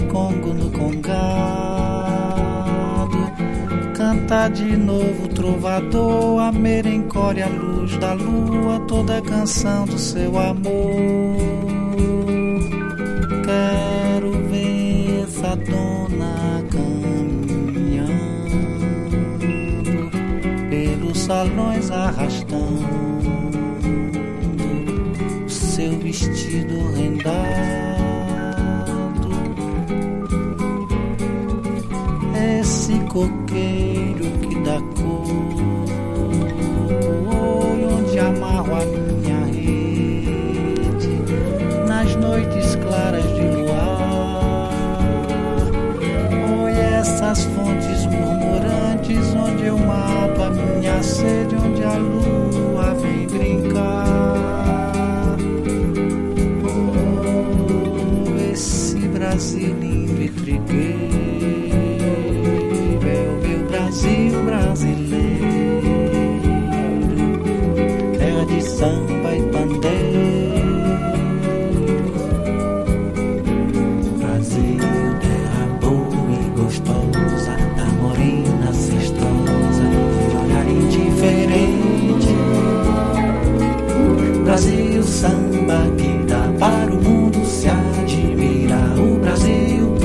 FREE SOUL